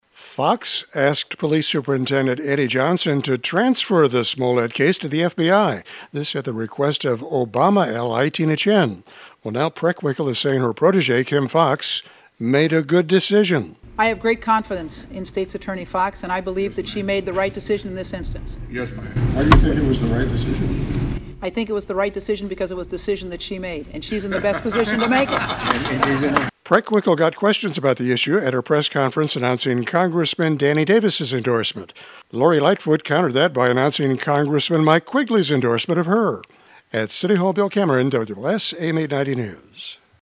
Preckwinkle got questions about the issue at her press conference announcing Congressman Danny Davis’ endorseement.